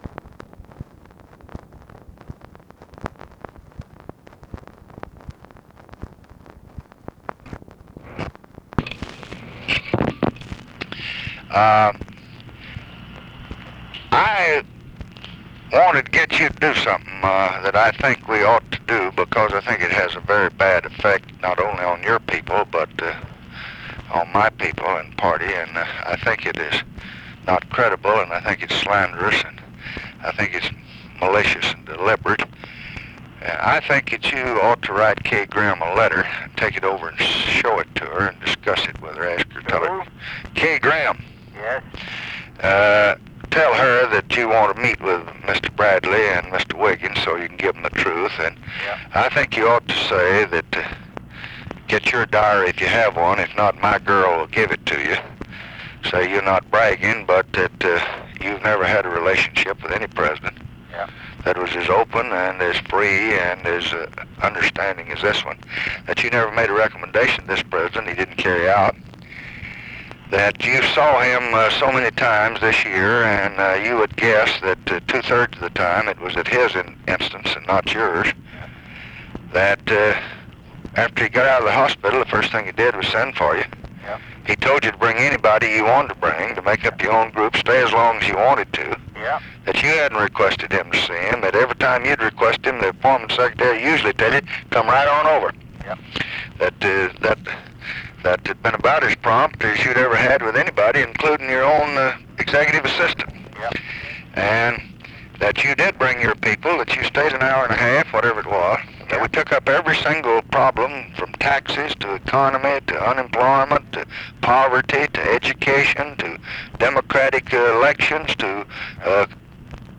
Conversation with GEORGE MEANY, December 22, 1966
Secret White House Tapes